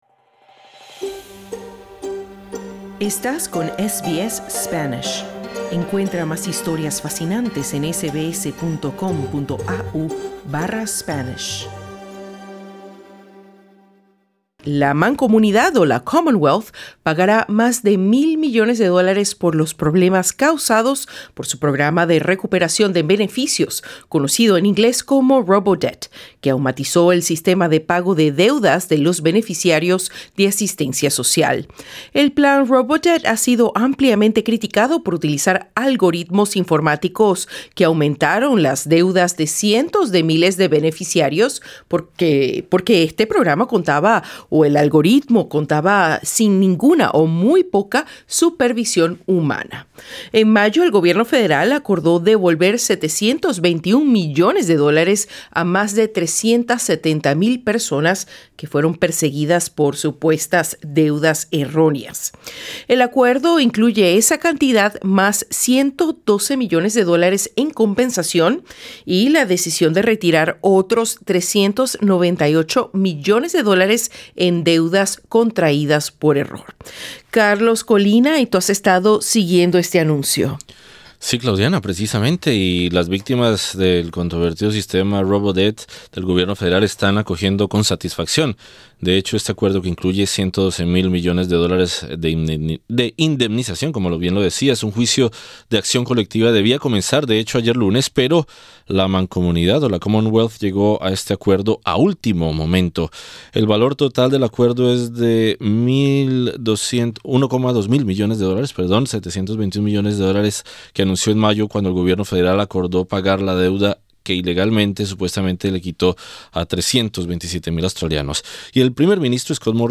Sobre esto conversamos con el exdiputado laborista del estado de Victoria, Telmo Languiller. Para escuchar la entrevista haz click en la imagen de portada.